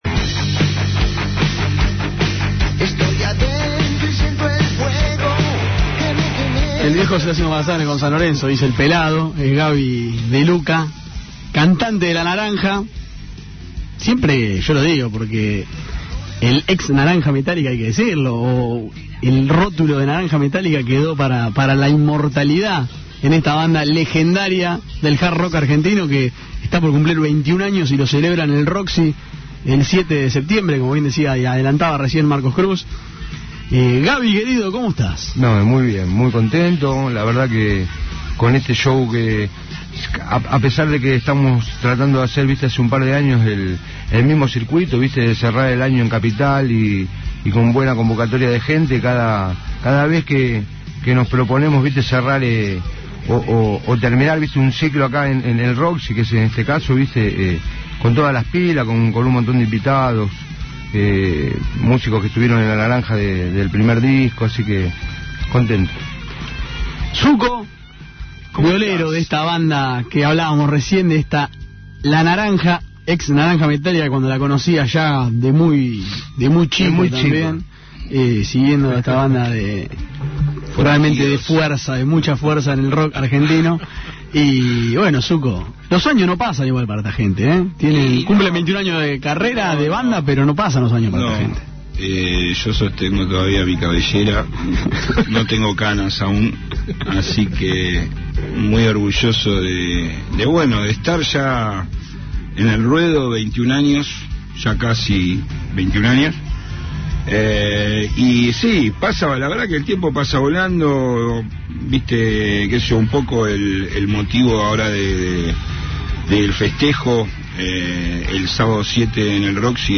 La Naranja habló con ASCENSO ROCK sobre sus próximos shows.